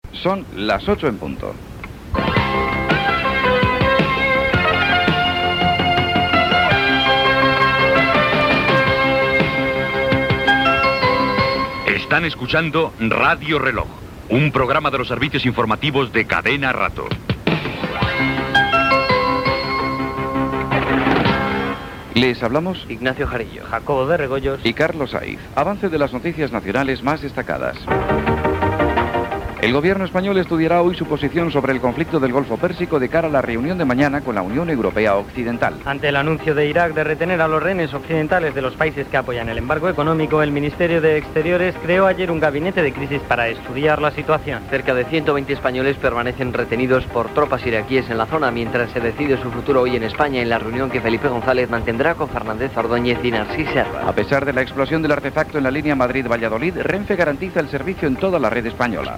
Hora, careta del programa, equip, resum d'informació nacional
Informatiu